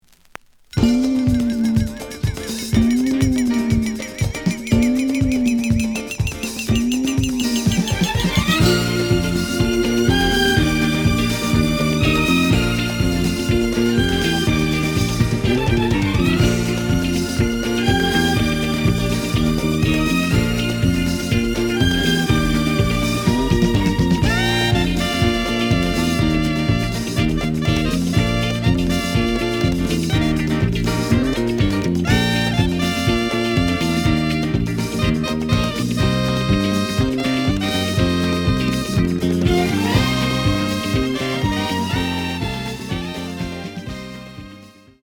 The audio sample is recorded from the actual item.
●Genre: Disco
Edge warp. But doesn't affect playing. Plays good.